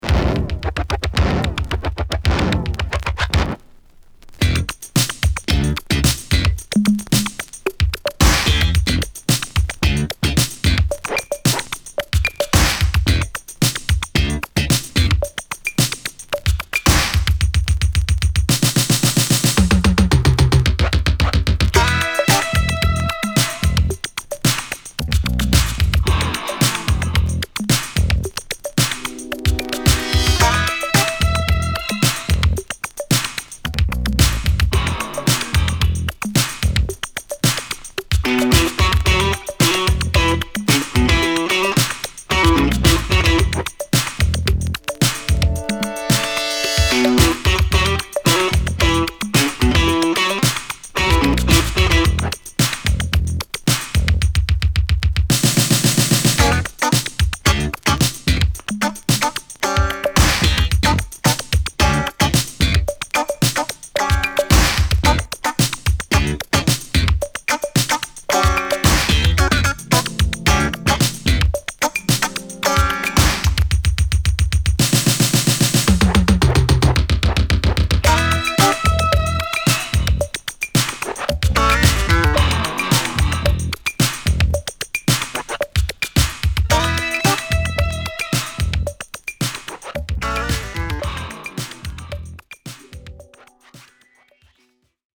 PS ��������Electro Boogie!!